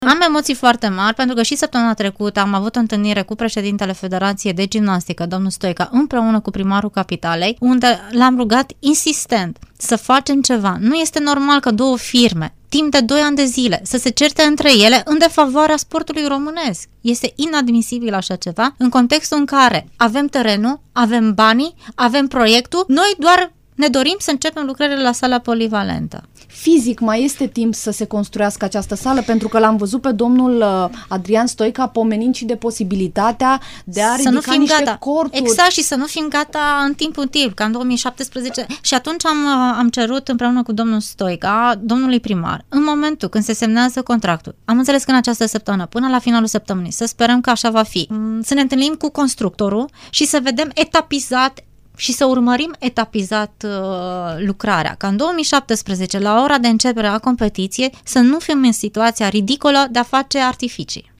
Ministrul Tineretului și Sportului Gabriela Szabo la Interviurile Europa FM